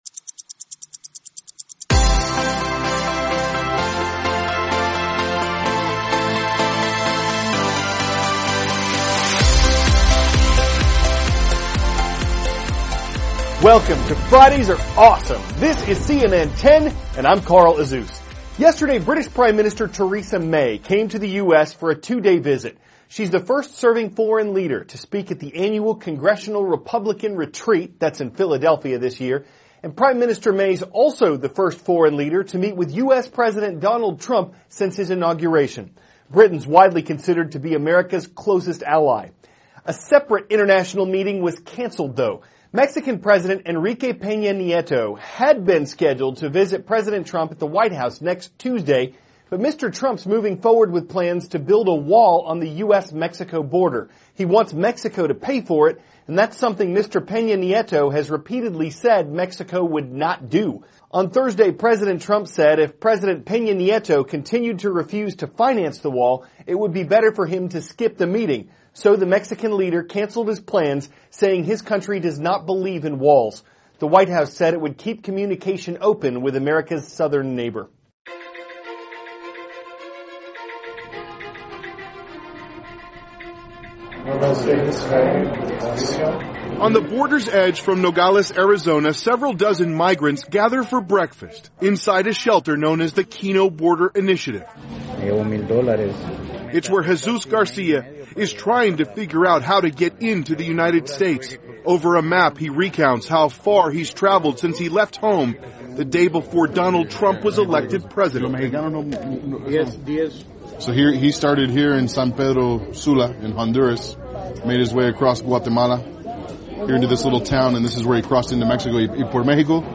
*** CARL AZUZ, cnn 10 ANCHOR: Welcome to Fridays are awesome!